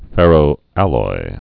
(fĕrō-ăloi, -ə-loi)